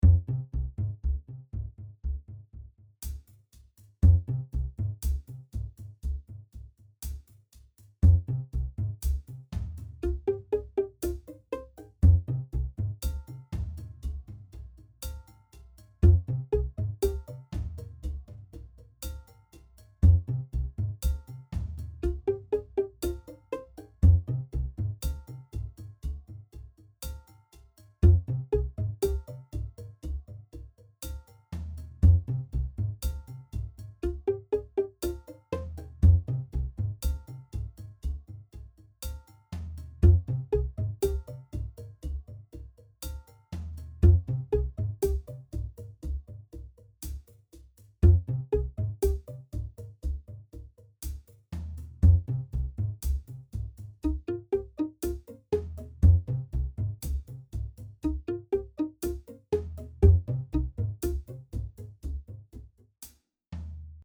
Ruckzuck packt mich der Schelm am Schlawittchen Ein Basston, ein bisschen Geklapper, billige Logic-Pizzicato-Dingbums, alles per Send auf eine Quadravox-Instanz mit Preset. Also ich muss lachen Toy-Music könnte man das nennen, oder?